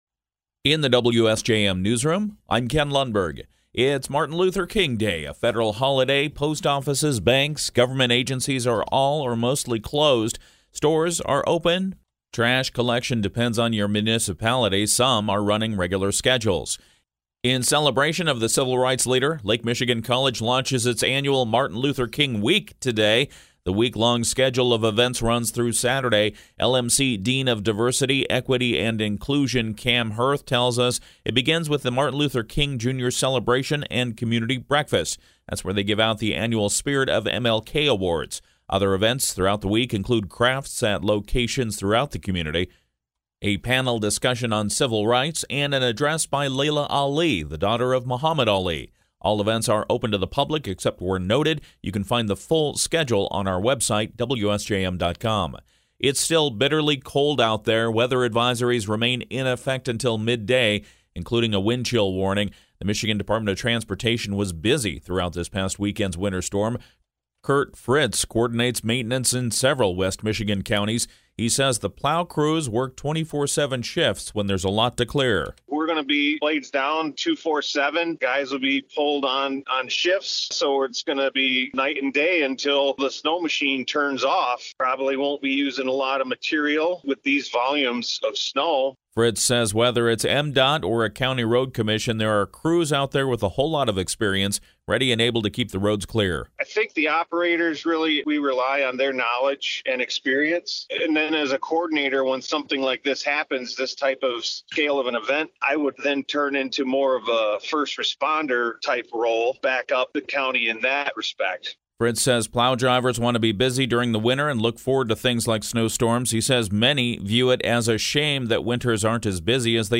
Ionia Vocational Village Tour - MGA’s interview starts at 4:33